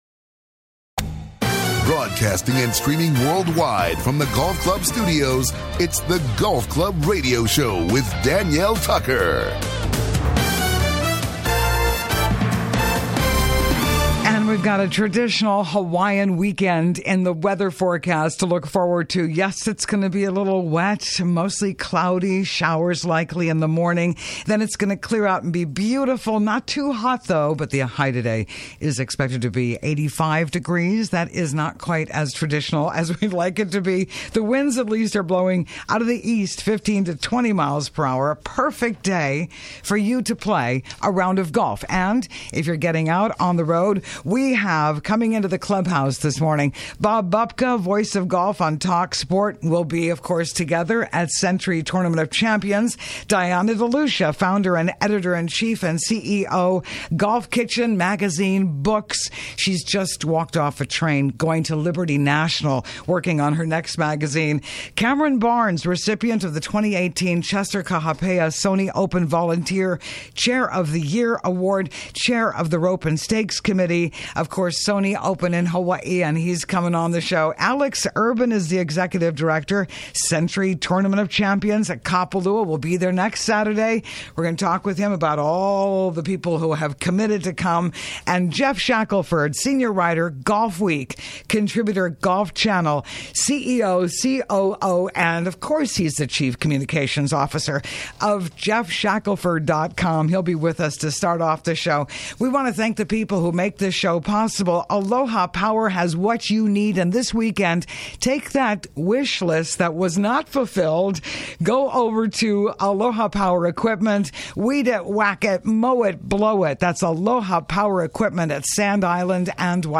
The Golf Club Radio Show� broadcasting world-wide from Hawaii.